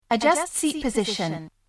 The sounds were recorded onto Minidisk directly from the output of the Model 1 sound boards played in test mode, then recorded onto a PC in 44.1KHz 16 bit stereo.
The files with an "sp_" prefix are speech, whereas the rest are music or effects.